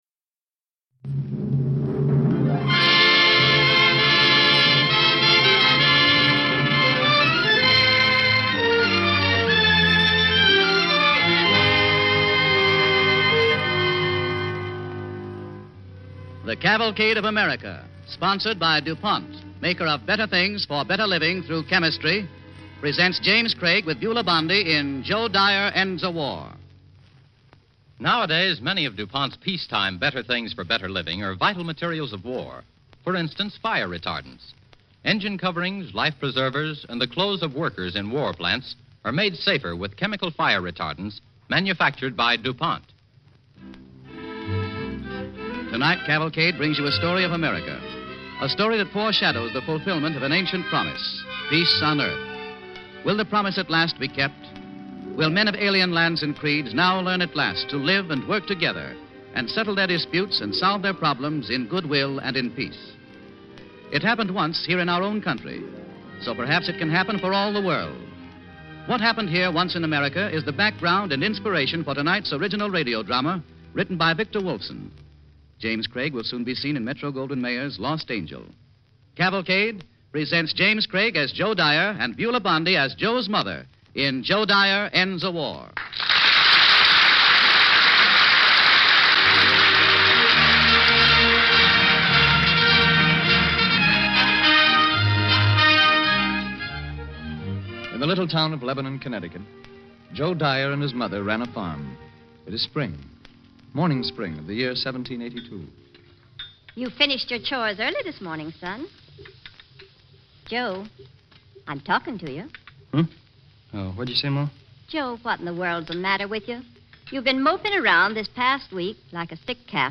Joe Dyer Ends a War, starring James Craig and Beulah Bondi